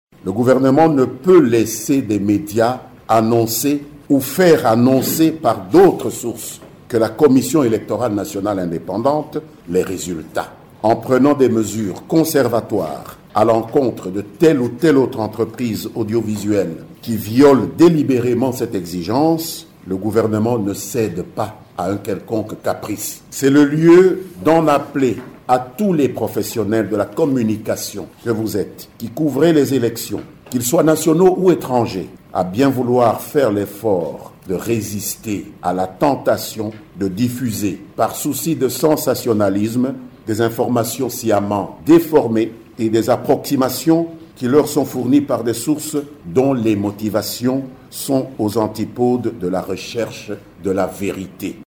Dans cet extrait sonore, le ministre Mende a appelé les journalistes tant nationaux qu’étrangers de ne pas céder à la tentation de diffuser, par souci de sensationnalisme, des informations sciemment déformées :